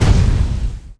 Index of /App/sound/monster/skeleton_magician
attack_3_explo.wav